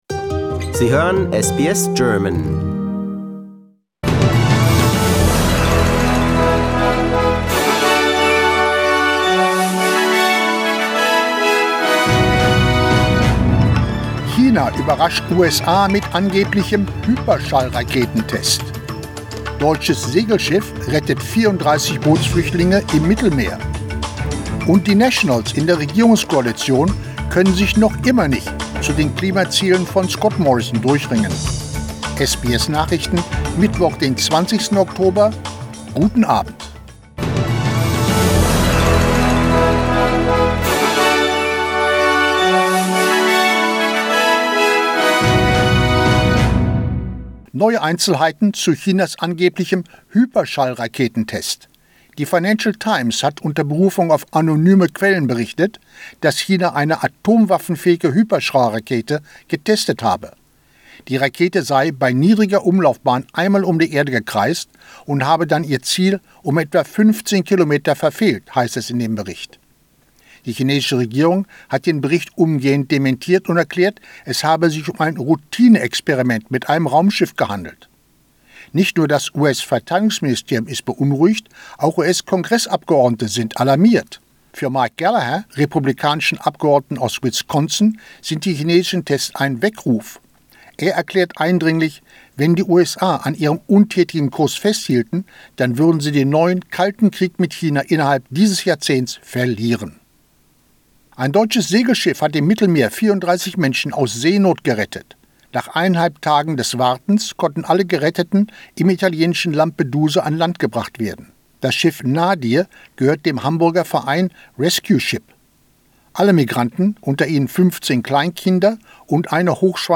SBS Nachrichten, Mittwoch 20.10.21